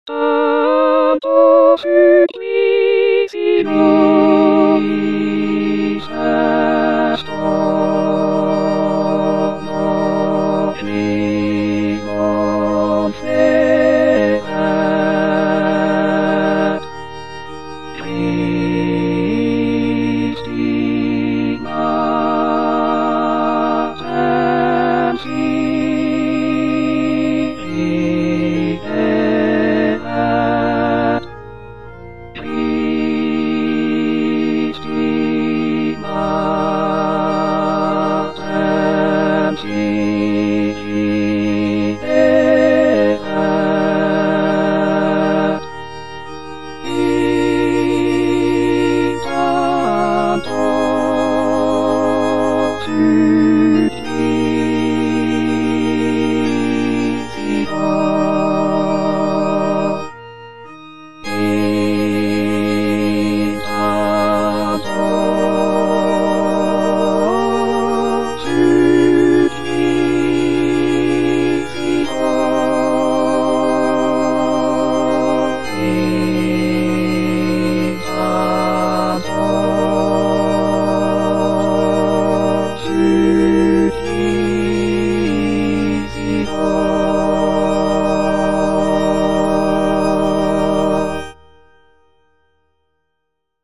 Parole 3: Mulier, ecce filius tuus        Prononciation gallicane (à la française)
Tutti